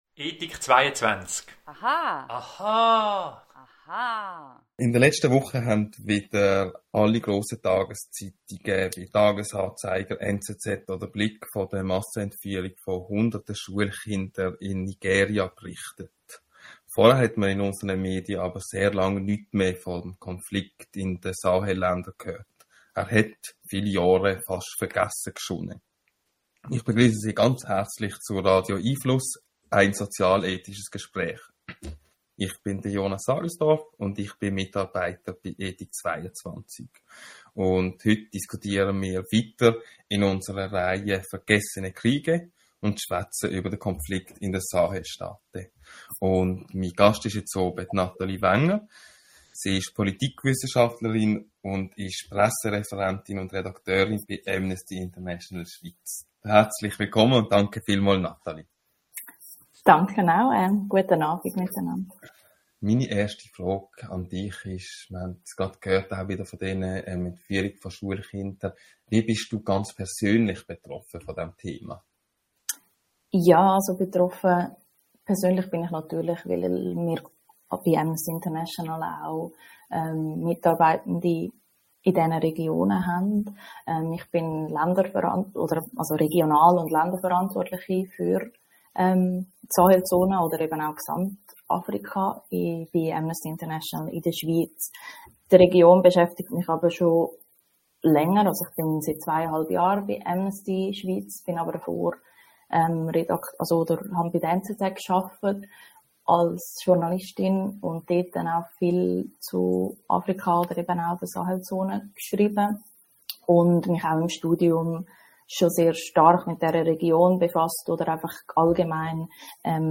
Podcast hier zuhören Radio🎙einFluss findet jeden Mittwoch 18:30 - 19 Uhr statt .